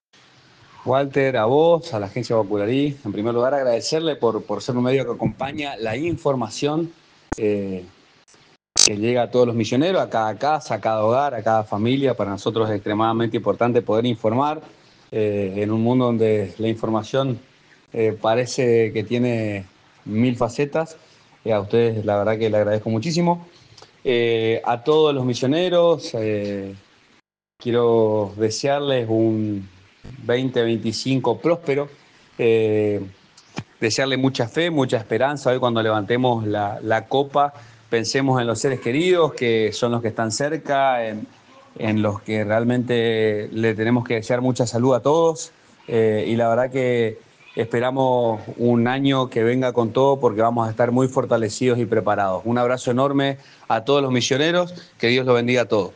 Matías Vilchez, Intendente de San Javier, saludó a todos los misioneros y deseo un prospero 2025.